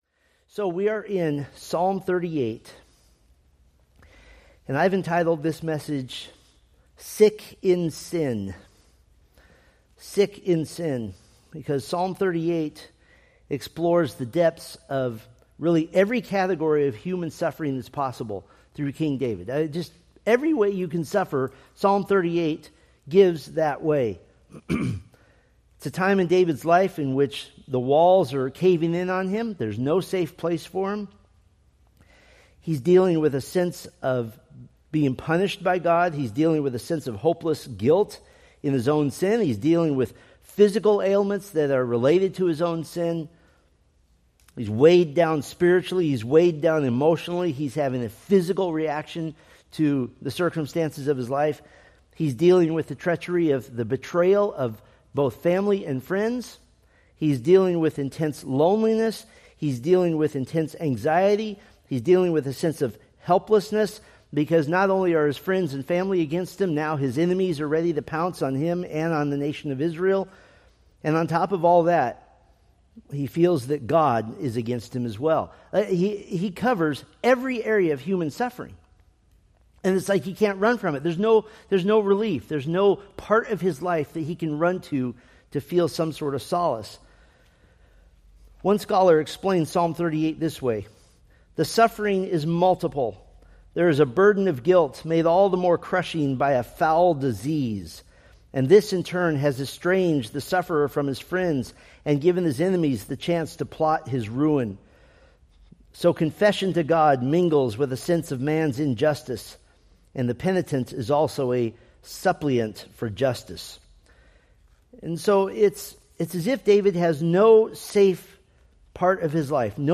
Date: Aug 10, 2025 Series: Psalms Grouping: Sunday School (Adult) More: Download MP3 | YouTube